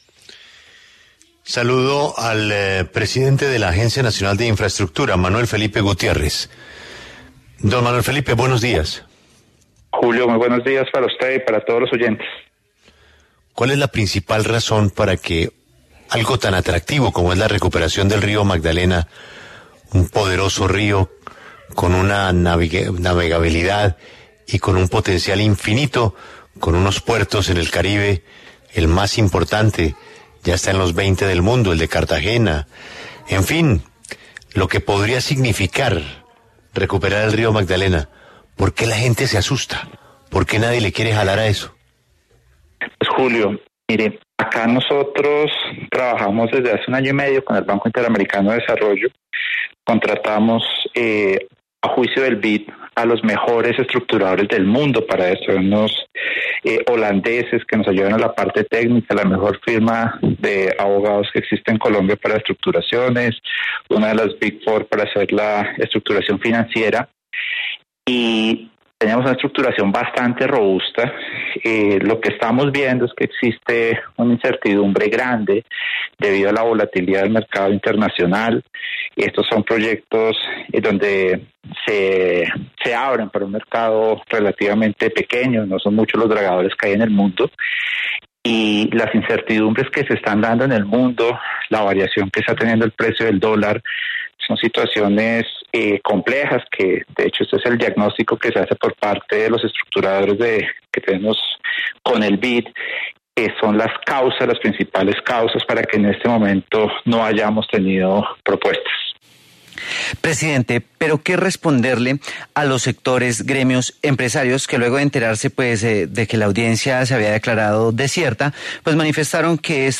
En diálogo con La W, Manuel Felipe Gutiérrez, presidente de la Agencia Nacional de Infraestructura, se pronunció sobre el cierre de ofertas de la APP del río Magdalena al que no se presentó ningún oferente.